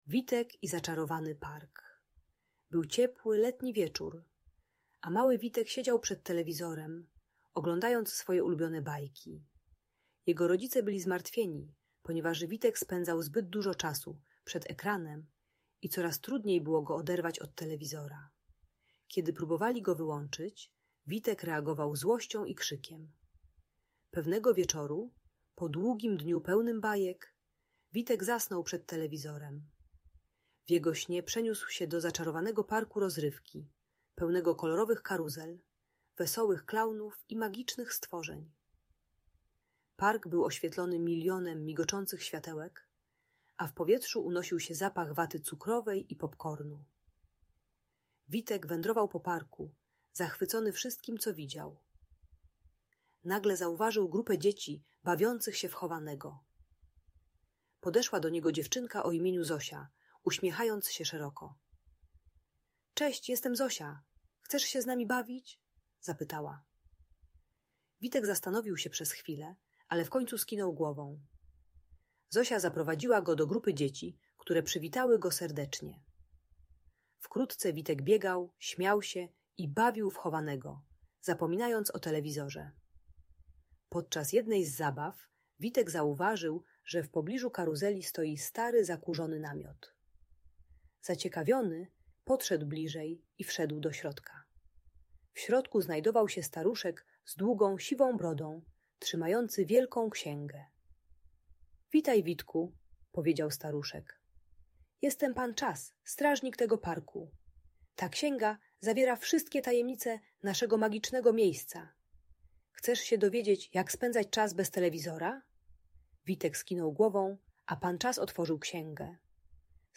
Historia o Witku i Zaczarowanym Parku - Audiobajka